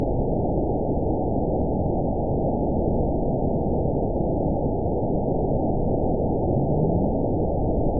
event 919935 date 01/28/24 time 00:26:32 GMT (4 months, 1 week ago) score 9.13 location TSS-AB08 detected by nrw target species NRW annotations +NRW Spectrogram: Frequency (kHz) vs. Time (s) audio not available .wav